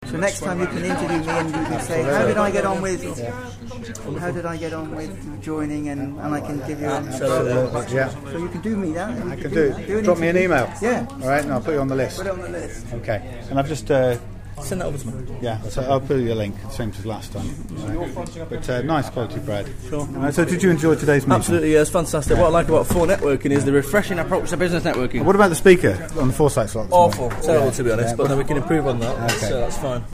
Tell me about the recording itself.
Sample of live 4N chit chat